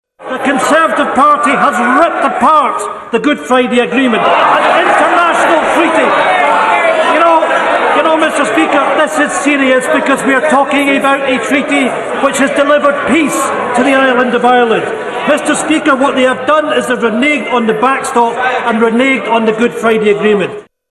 The SNP’s Ian Blackford accused Tory MPs of not honouring the Good Friday Agreement: